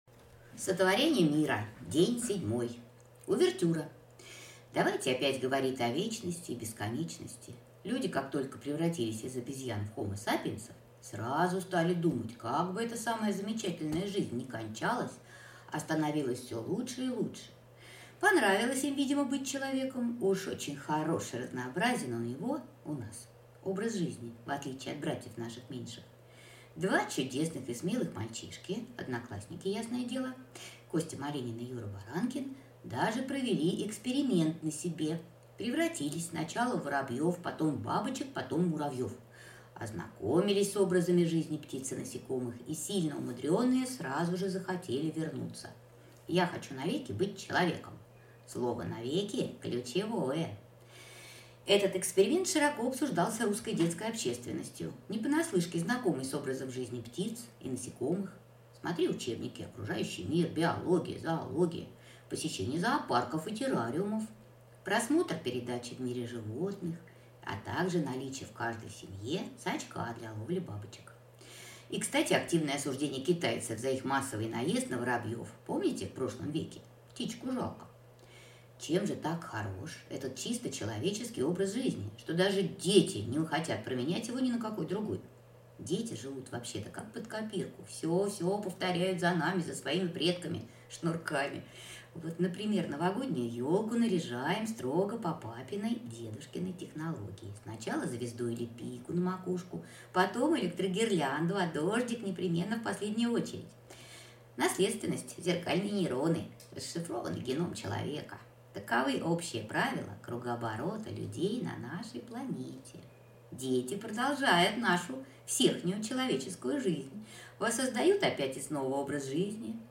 Аудиокнига Сотворение мира. День Седьмой | Библиотека аудиокниг